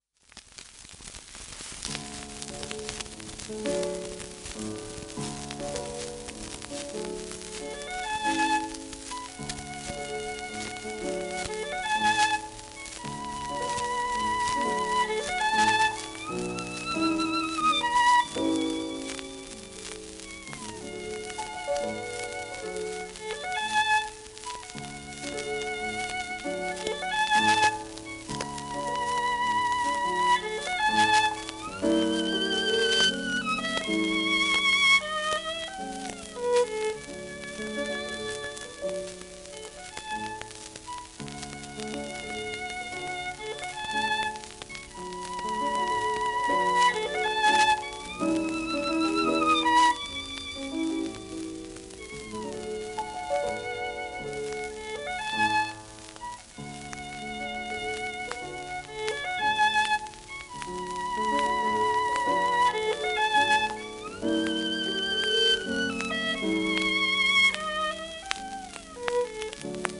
w/ピアノ
シェルマン アートワークスのSPレコード